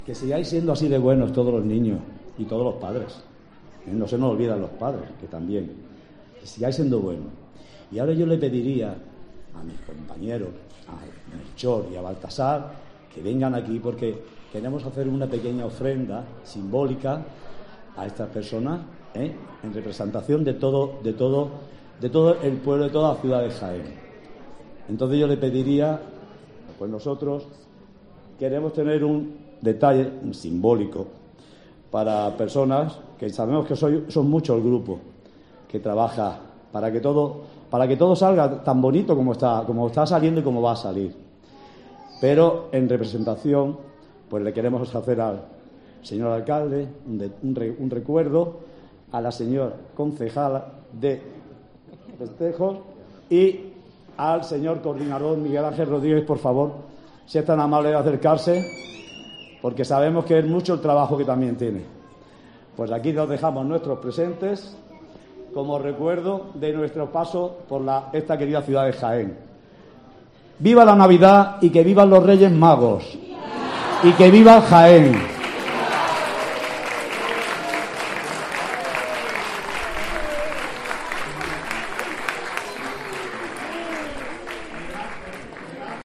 El Rey Melchor en el salón de plenos del Ayuntamiento de Jaén